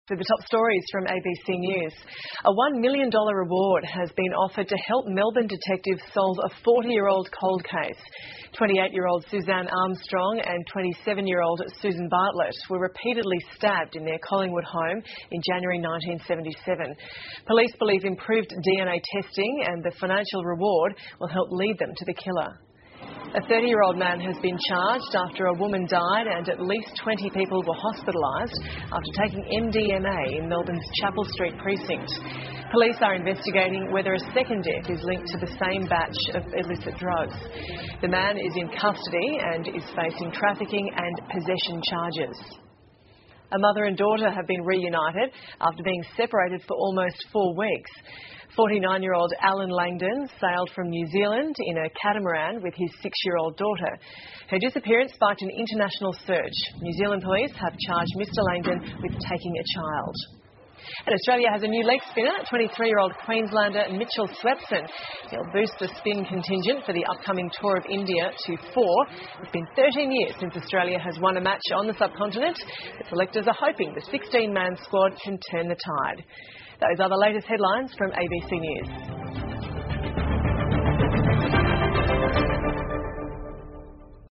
澳洲新闻 (ABC新闻快递) 墨尔本警方悬赏捉拿40年悬案凶手 听力文件下载—在线英语听力室